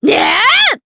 Nicky-Vox_Attack3_jp.wav